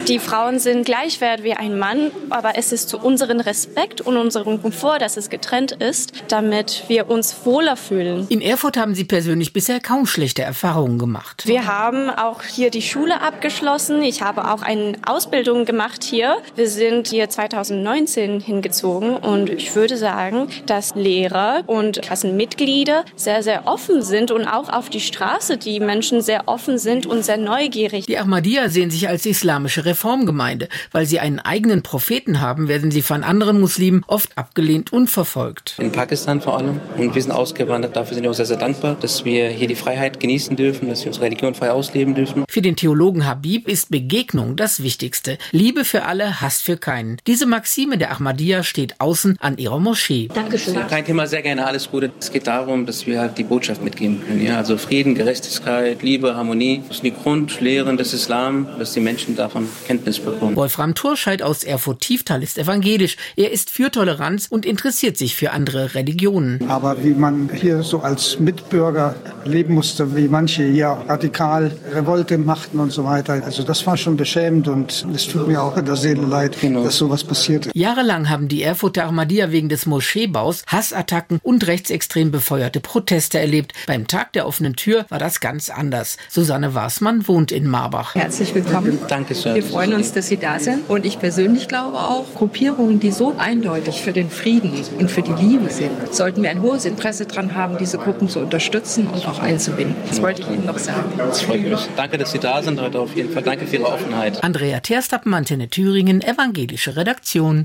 Jetzt ist sie offiziell eröffnet, die Moschee der Ahmadiyya-Gemeinde in Erfurt-Marbach. Und gleich letzten Sonntag gabs einen „Tag der Offenen Tür“ mit ca. 800 Gästen. Gespräche drehten sich oft um die Rolle der Frauen.